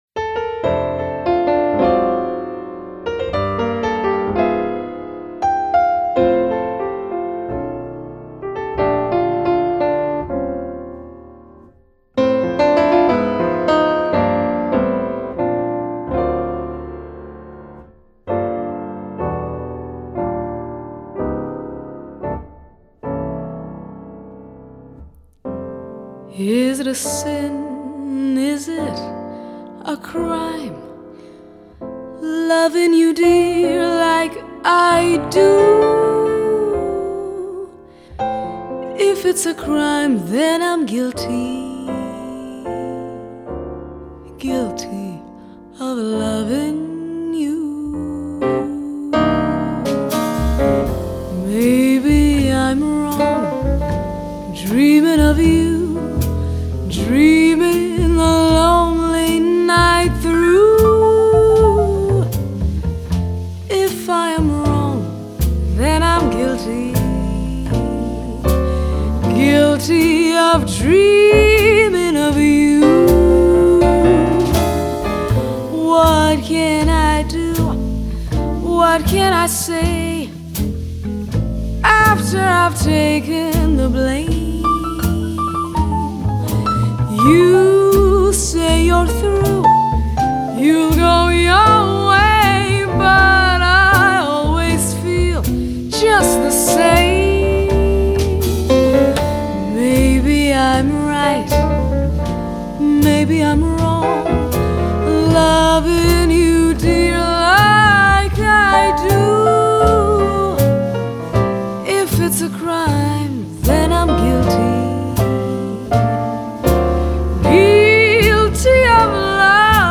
從各國民謠抒情曲 爵士經典曲到當代都會小品
非常夜的感覺 一種精緻華麗的音樂感官享受 一張絕對可以滿足各個樂迷的當代爵士女聲專輯
本張專輯有著絕佳的錄音效果